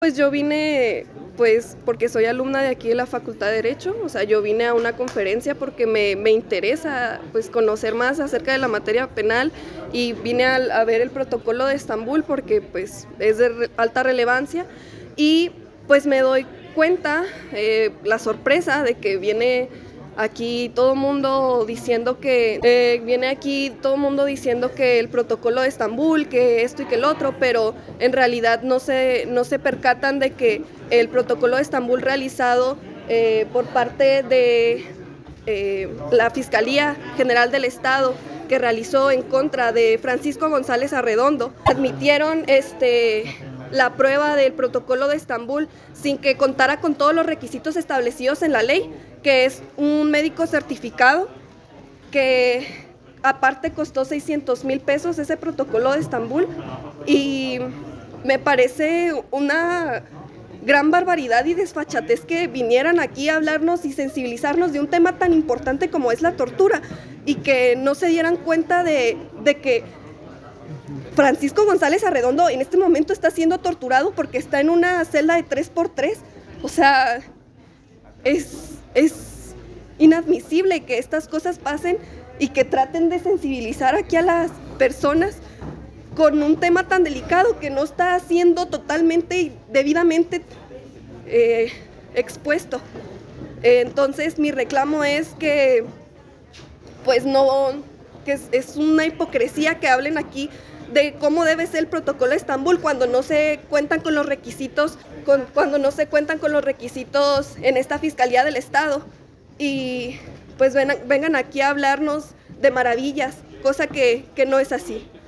Estudiante-de-Derecho-sobre-Protocolo-de-Estambul.mp3